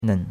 nen3.mp3